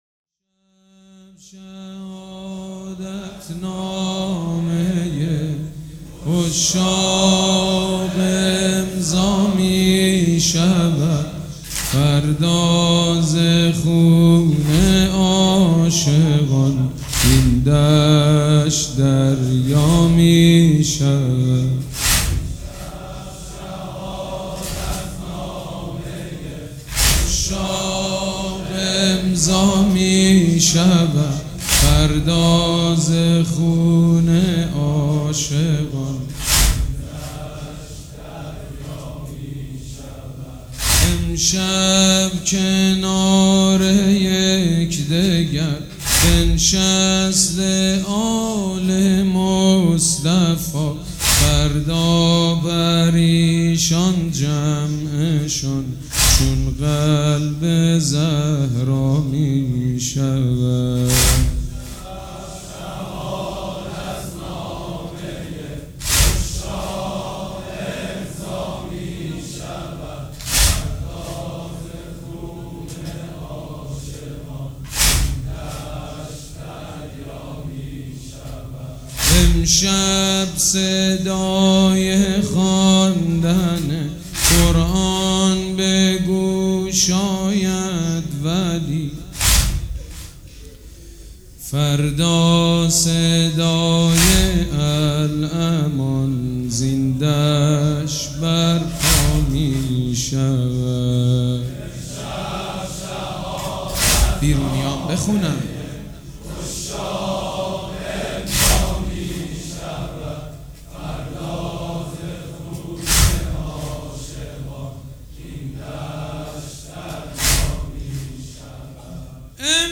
مداح
مراسم عزاداری شب عاشورا